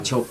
[t͡ɕop̚] noun fish net